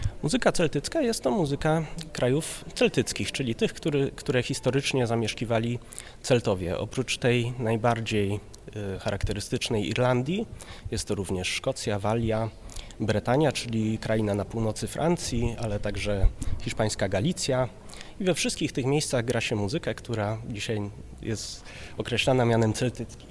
W rozmowie z Radiem Rodzina opowiada o swoim zainteresowaniu muzyką celtycką, ale też i o tym jak „załapać takiego bakcyla”.